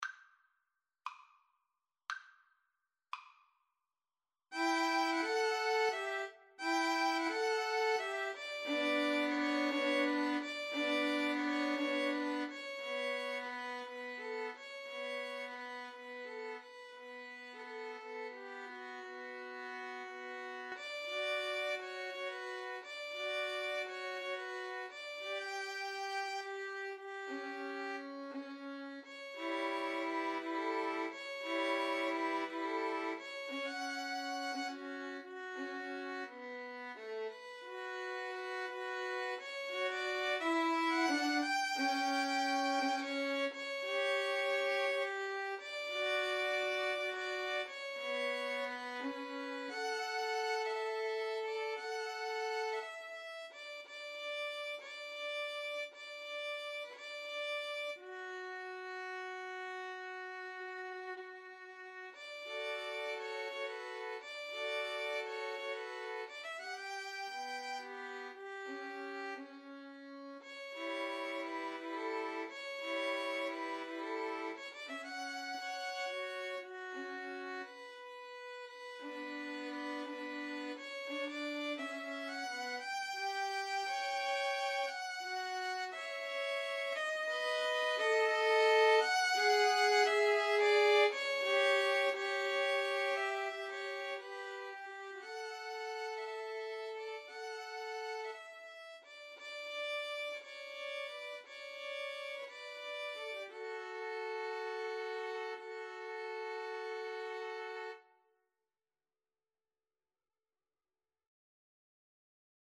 6/8 (View more 6/8 Music)
Classical (View more Classical 2-Violins-Cello Music)